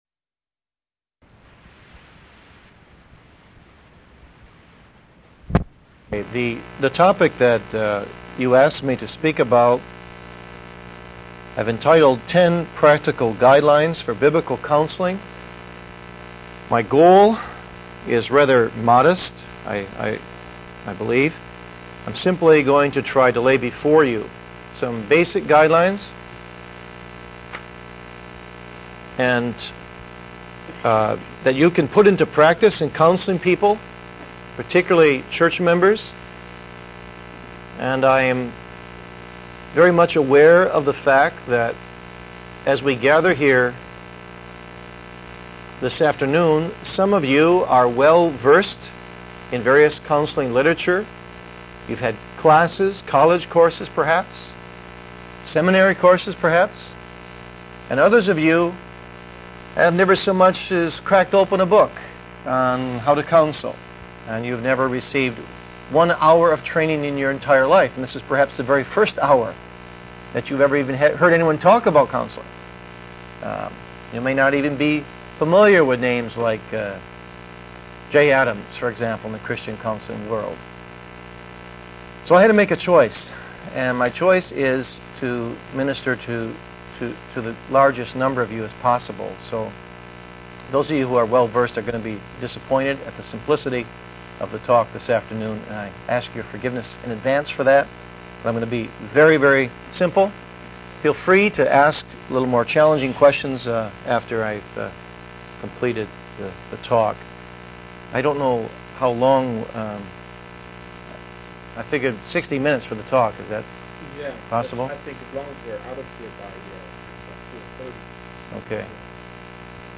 Office Bearer's Conference